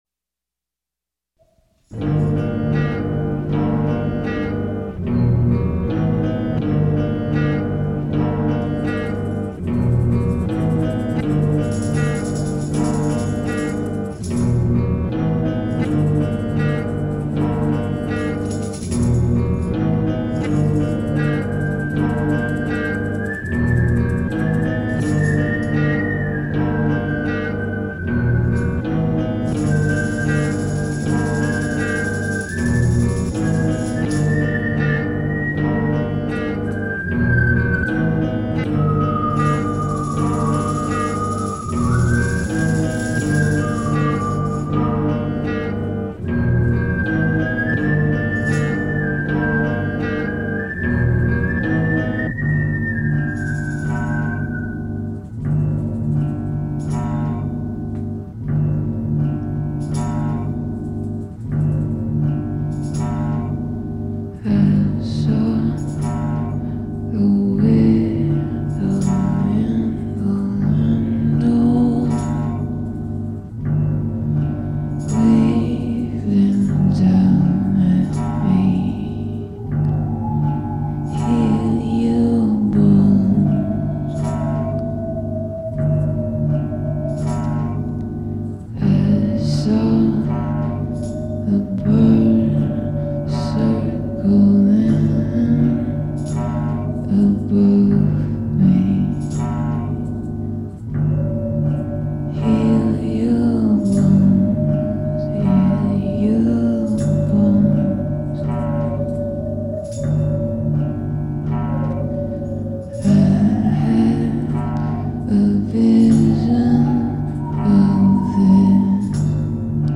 *фоновая композиция –